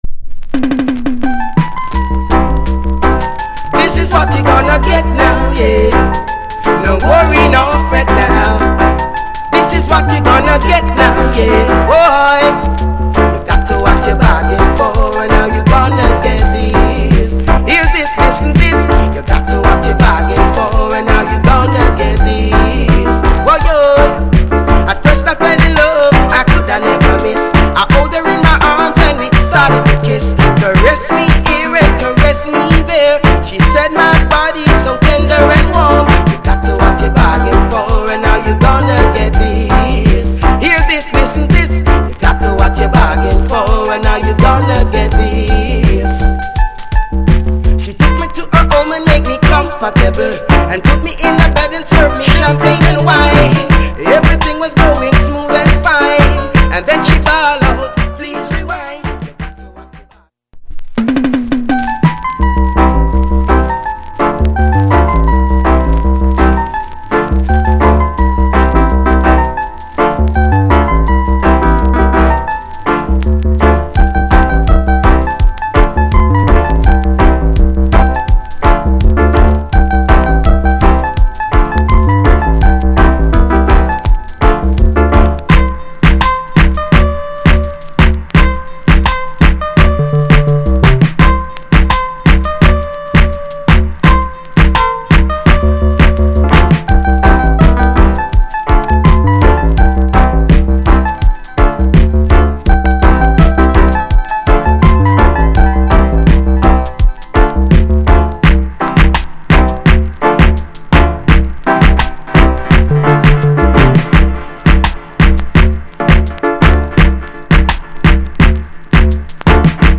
* 80's Good Vocal